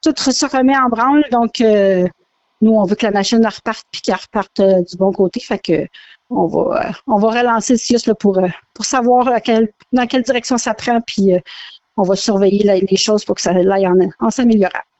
En entrevue, la mairesse a mentionné qu’elle va continuer de discuter avec la direction du CIUSSS MCQ.